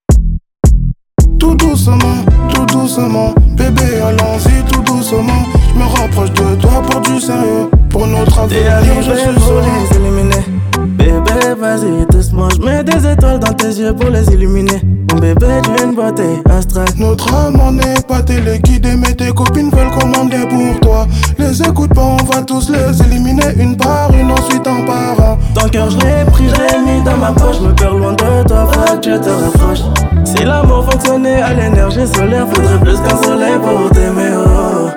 Жанр: Поп / Африканская музыка
# Afro-Pop